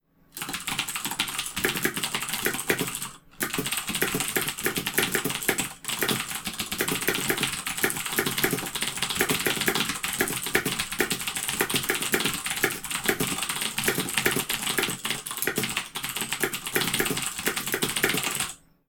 Fast typing on a mechanical keyboard
das keyboard mechanical race typing sound effect free sound royalty free Memes